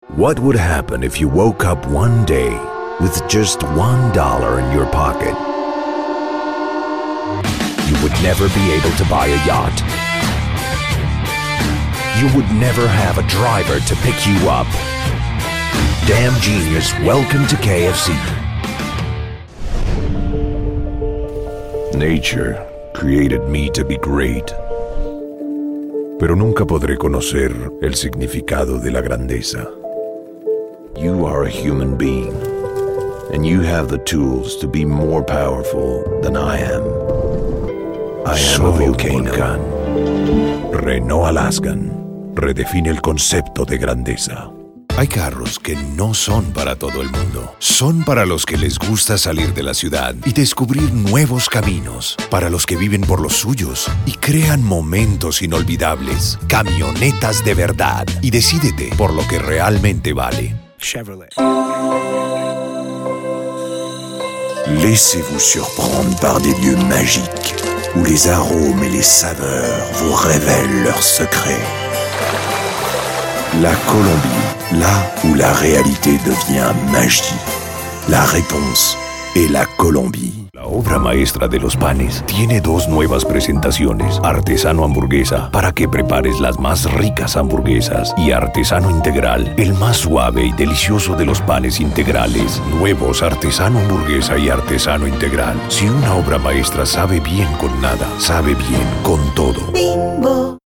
Voice Artists - Character
DEMO REEL ENG:SPA:FR 25.mp3